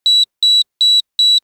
BEEP-Bips-de-digicode-ID-0758-LS.wav